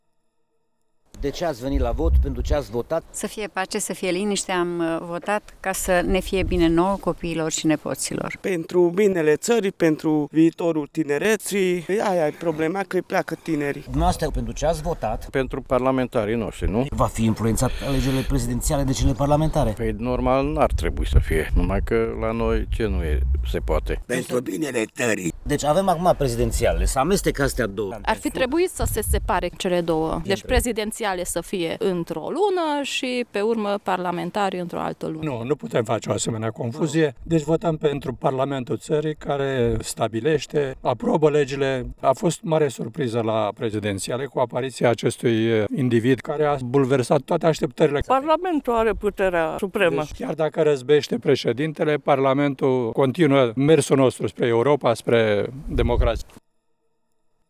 La circumcripțiile electorale din Târgu Mureș la primele ore ale dimineții cei mai conștiincioși și mai matinali au fost vârstnicii.